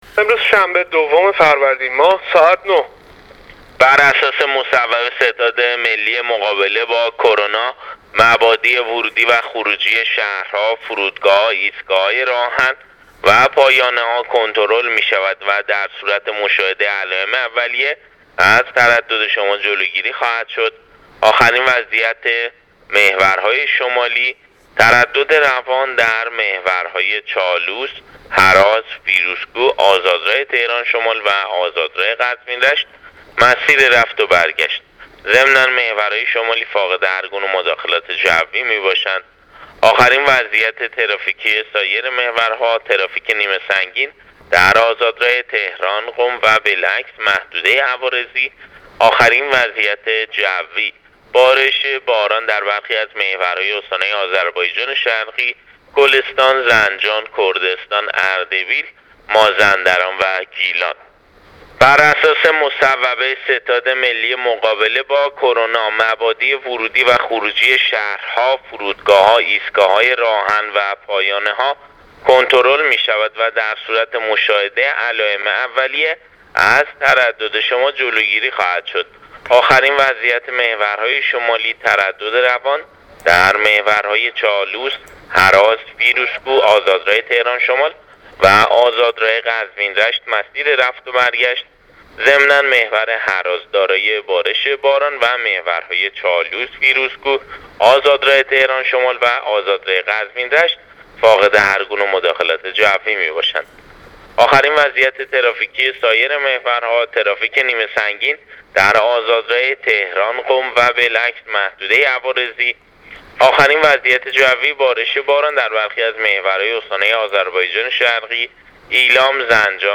گزارش رادیو اینترنتی از آخرین وضعیت ترافیکی جاده‌ها تا ساعت ۹ شنبه دوم فروردین ۱۳۹۹: